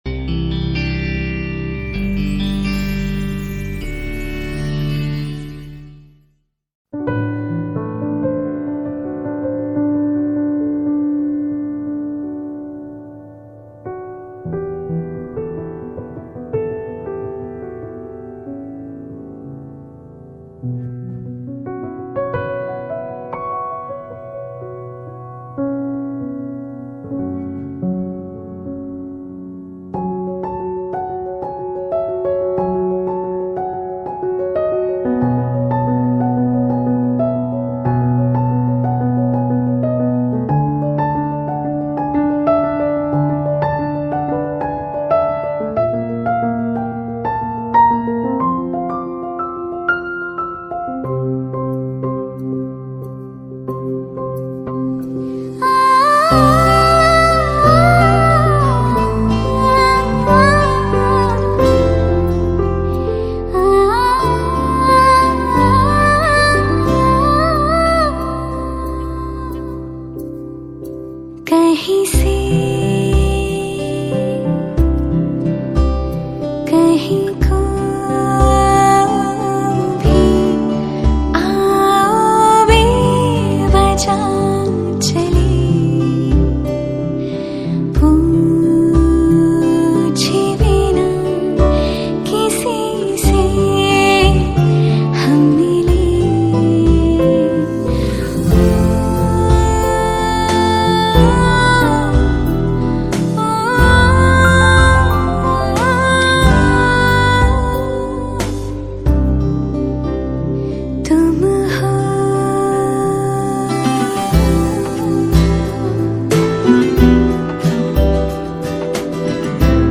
Hindi Mixtape Songs